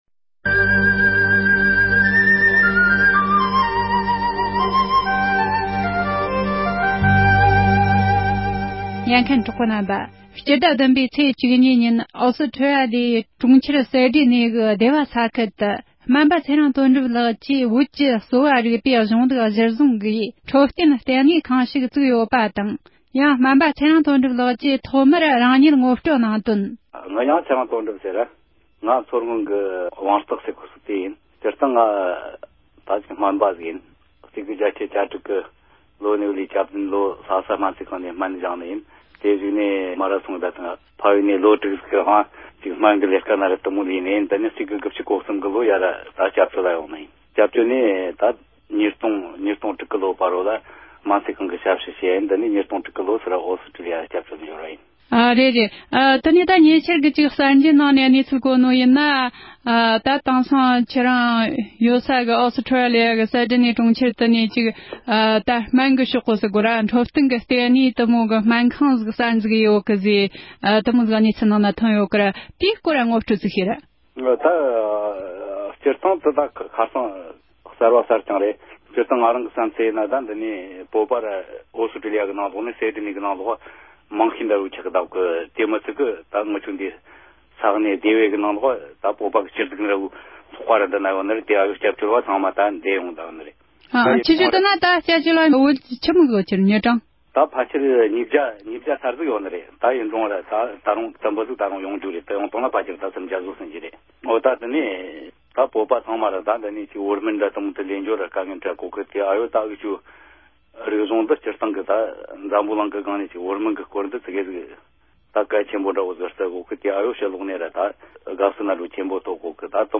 སྒྲ་ལྡན་གསར་འགྱུར། སྒྲ་ཕབ་ལེན།
གླེང་མོལ་